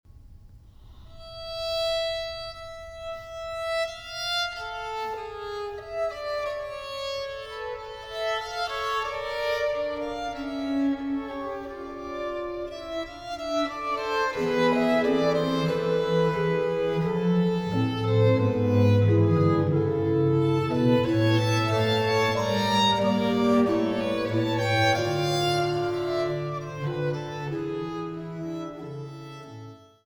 durch Flöten, Hackbrett und diverse Continuo-Instrumente